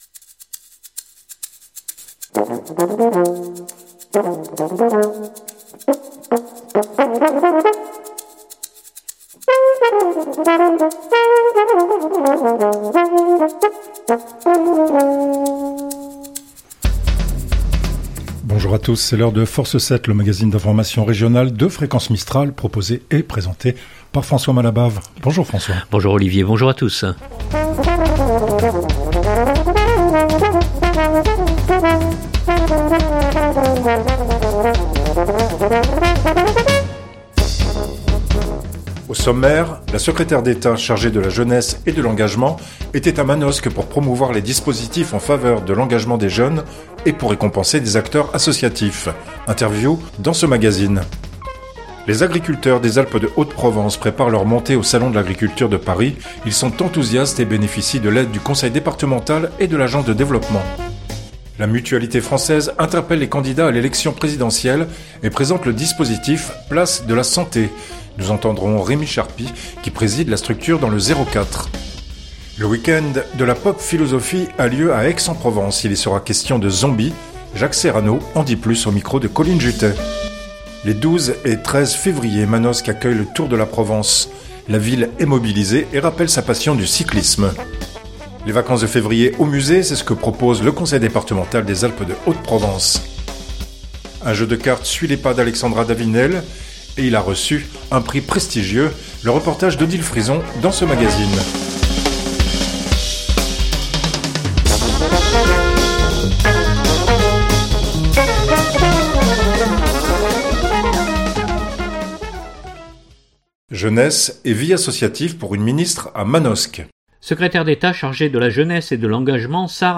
Interview dans ce magazine.